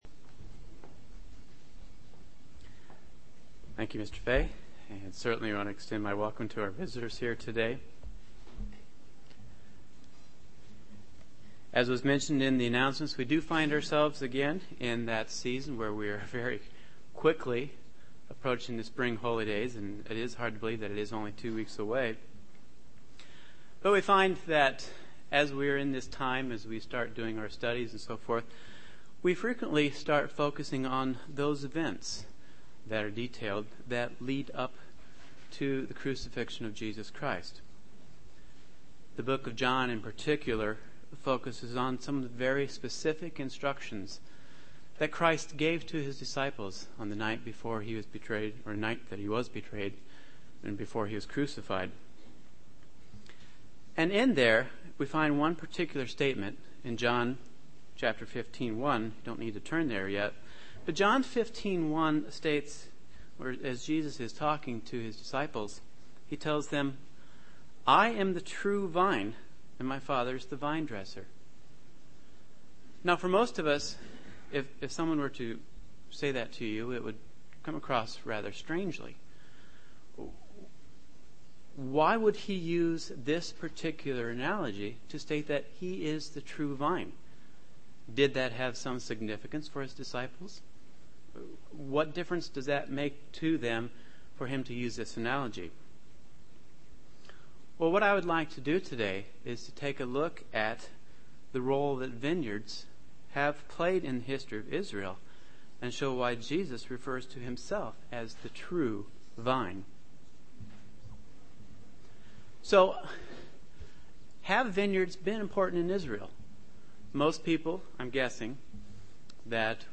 Given in Chicago, IL
The only way we can produce the true fruit of the Holy Spirit is to be securely connected to the true vine, who is Jesus Christ, UCG Sermon Studying the bible?